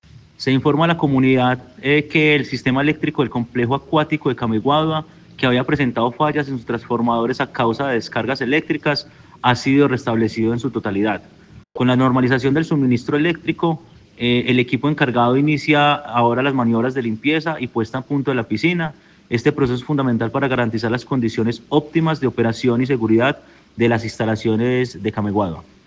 Juan Manuel Marín, secretario General de la Gobernación de Caldas.